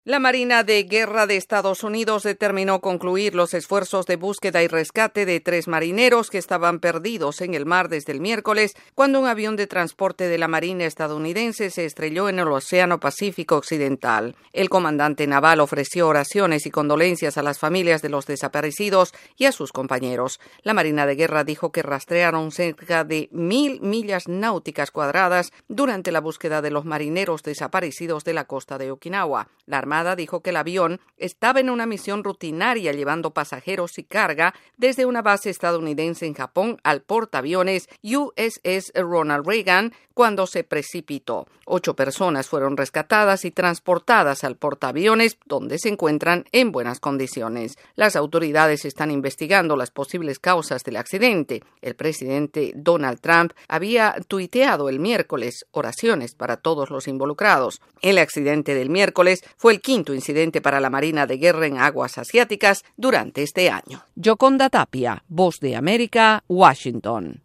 Autoridades militares decidieron suspender la búsqueda de tres marinos estadounidenses desaparecidos en un accidente de aviación en el mar de Filipinas. Desde la Voz de América en Washington DC informa